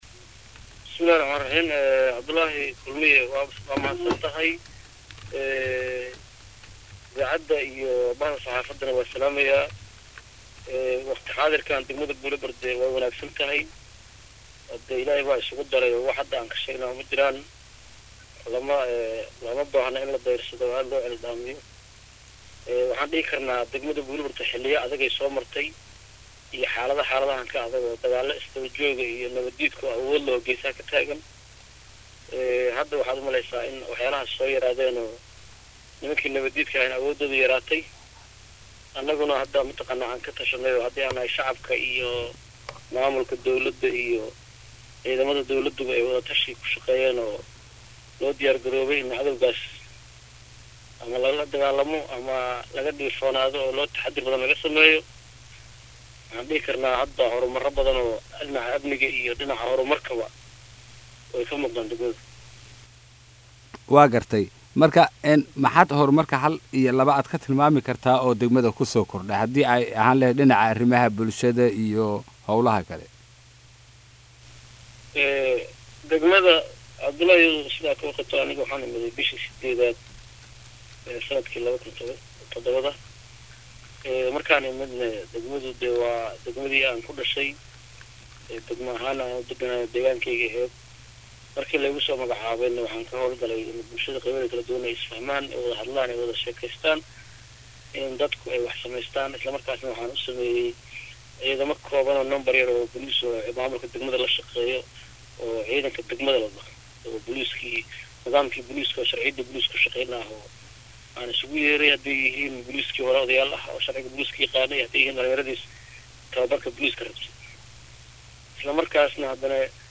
Guddoomiyaha Degmada Buulo barde ee Gobolka Hiiraan Cabdi Daahir karaare oo la hadlay Radio Muqdisho Codka Jamhuuriyadda Soomaaliya ayaa sheegay